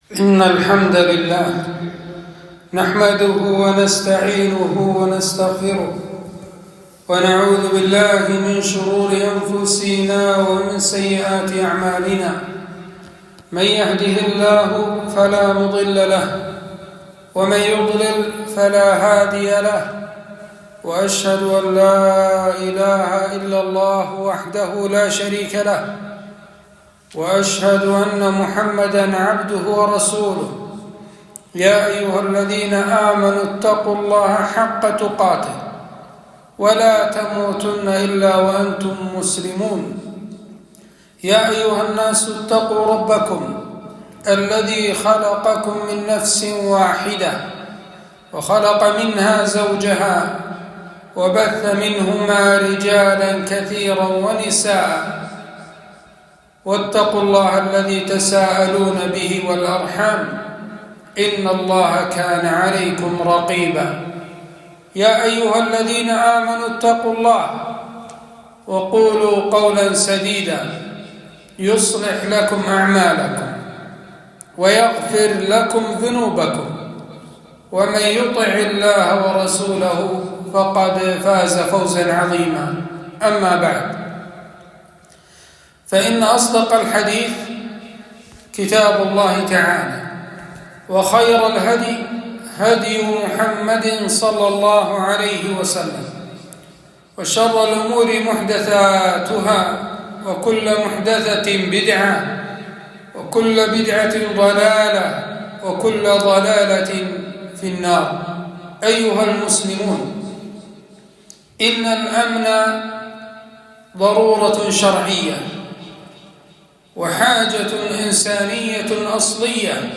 خطبة - أهمية الأمن في المجتمع